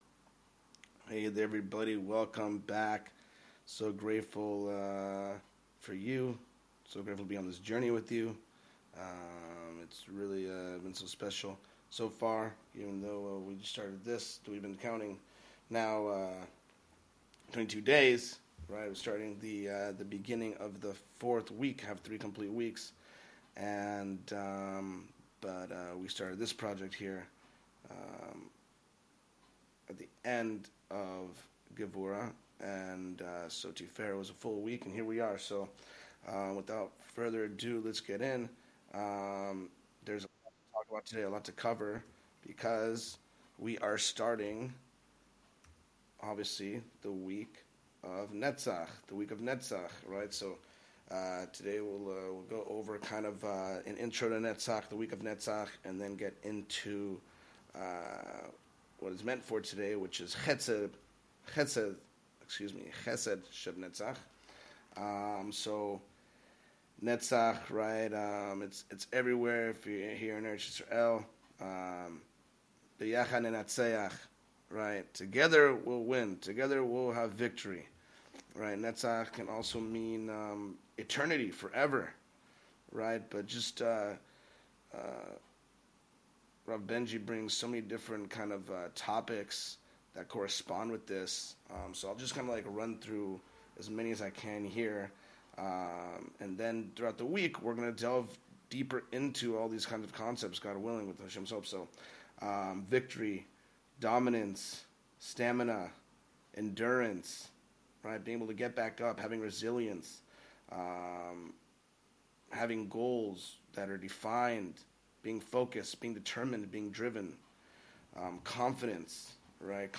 The shiur introduces the themes surrounding the week of Netzach, which represents victory, dominance, stamina, and endurance. It emphasizes the importance of confidence, resilience, and being masterful in our actions. Netzach is also exemplified by the concept of falling and getting back up, being adaptable, and evolving with the times.